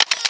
03 Camera Shutter.wav